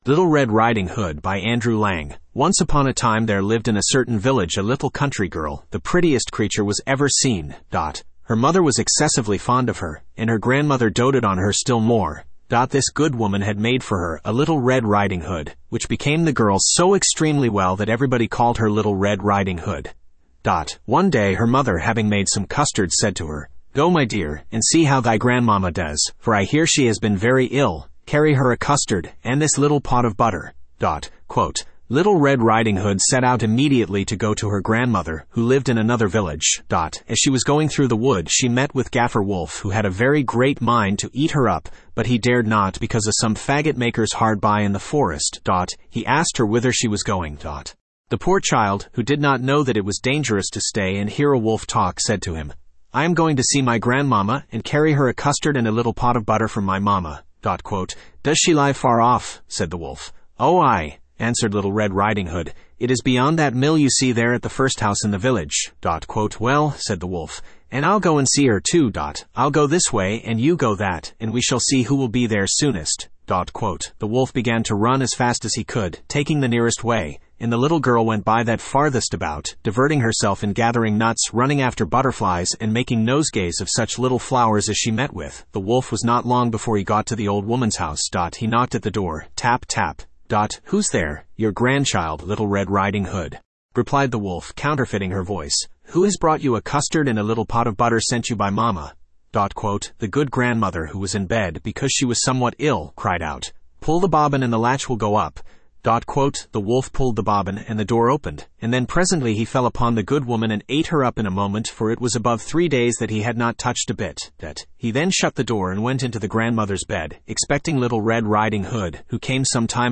Studio (Male)
little-red-riding-hood-en-US-Studio-M-c332ada8.mp3